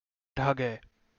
Captions English Kannada pronunciation of "dhage"